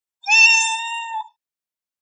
鳴いて甘える猫のかわいい鳴き声です。